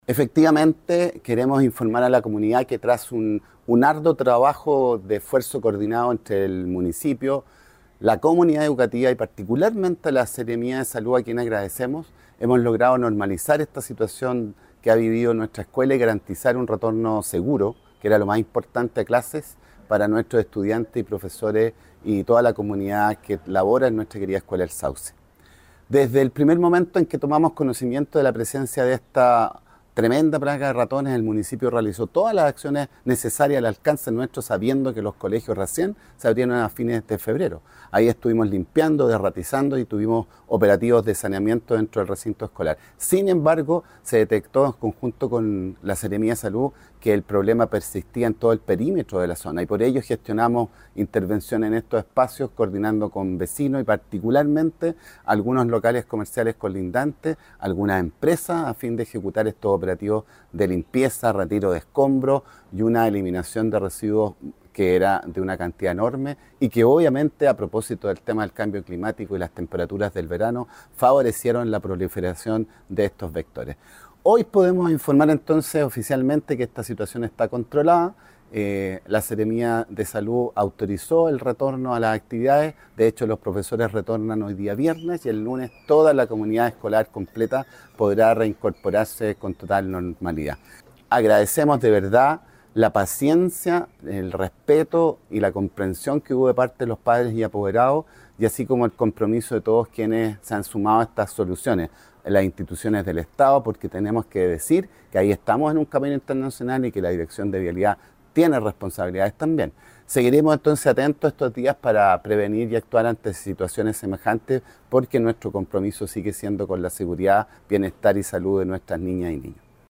El Alcalde Manuel Rivera destacó la importancia del trabajo coordinado entre las diversas instituciones.
Cuna-alcalde-Manuel-Rivera.mp3